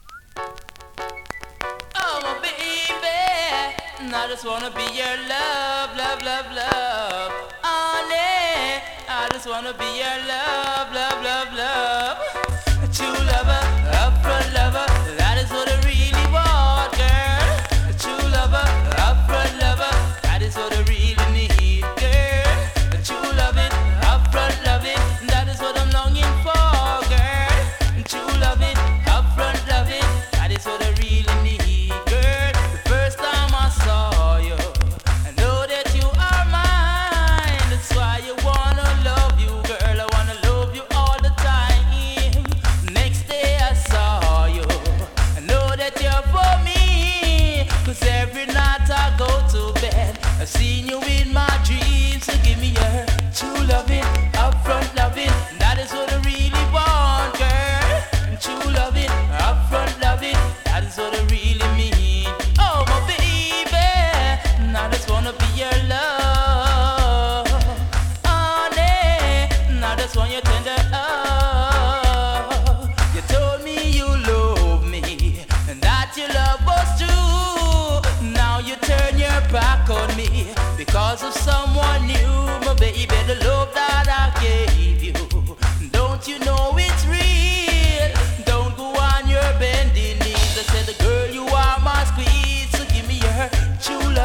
スリキズ、ノイズそこそこありますが